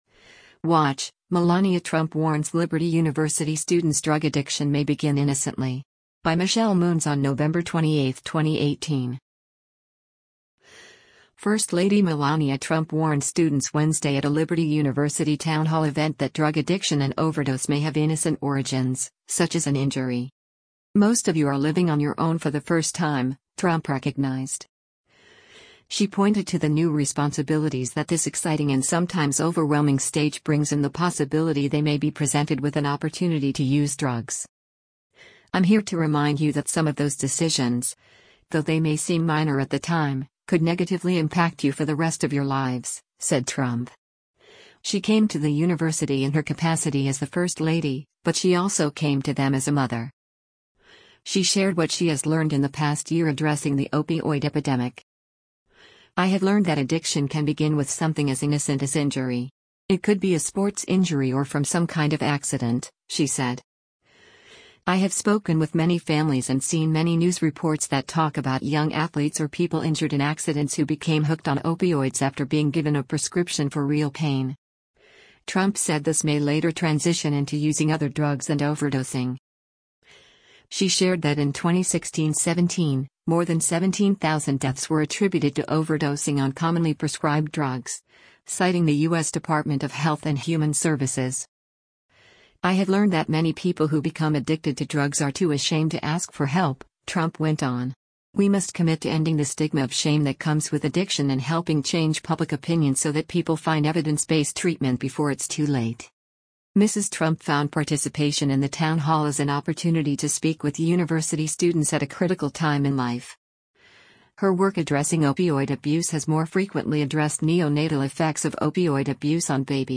First lady Melania Trump warned students Wednesday at a Liberty University town hall event that drug addiction and overdose may have innocent origins, such as an injury.
First lady Trump went on to share more of what she has learned and answered students’ questions.
Eric Bolling hosted the event, posted above.